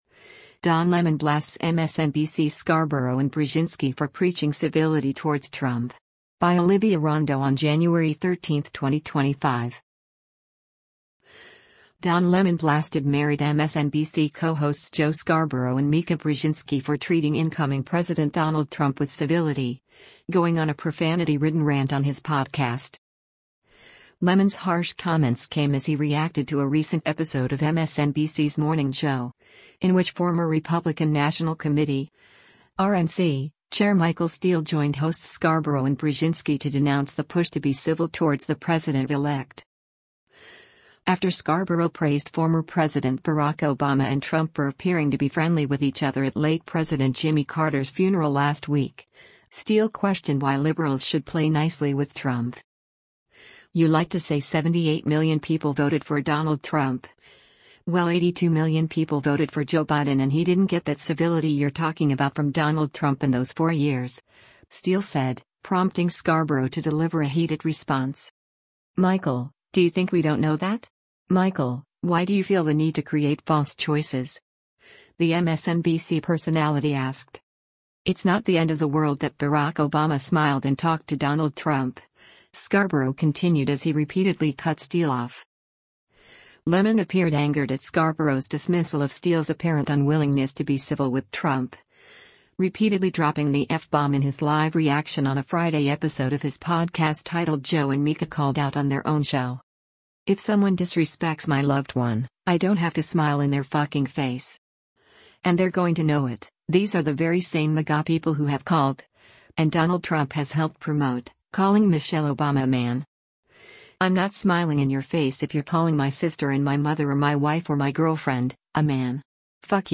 Lemon appeared angered at Scarborough’s dismissal of Steele’s apparent unwillingness to be civil with Trump — repeatedly dropping the “f-bomb” in his live reaction on a Friday episode of his podcast titled “Joe and Mika CALLED OUT on Their Own Show!”: